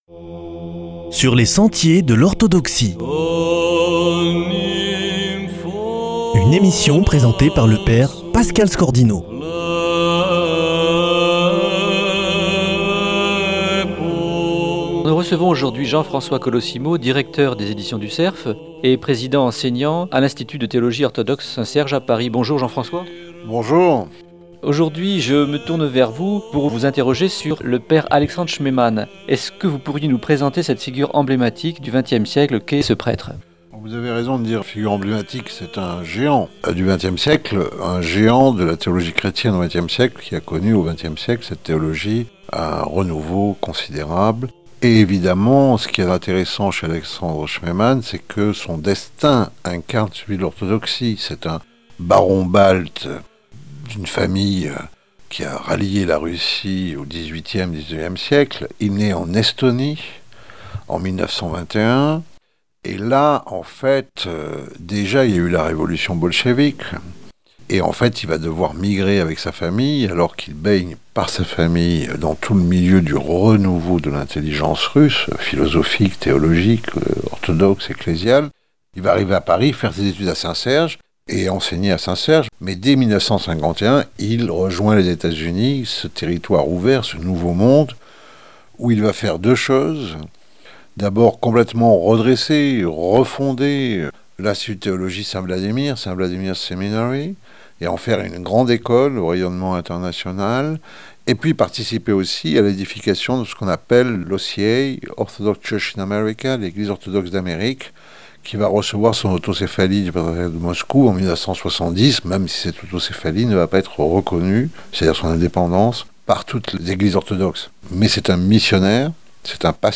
Musique de pause